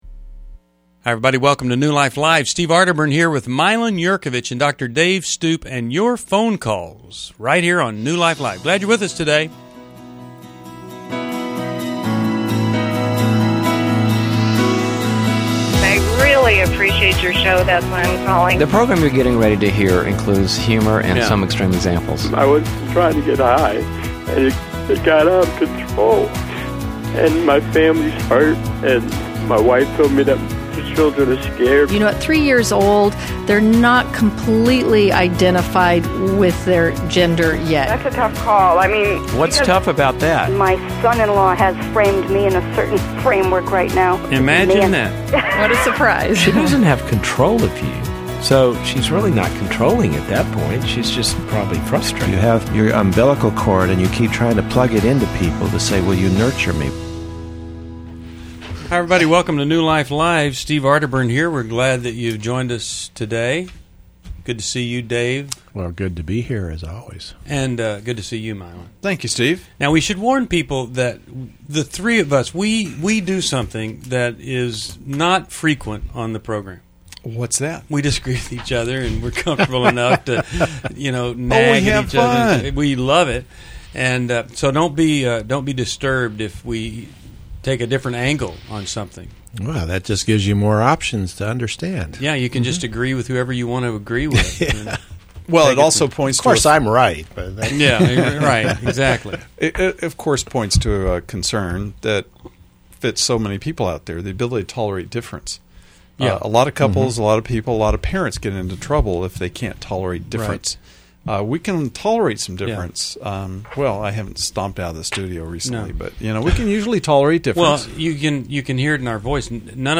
Join the conversation on New Life Live: August 18, 2011, as hosts tackle parenting teens, alcohol issues, divorce, and weight loss challenges.
Caller Questions: 1.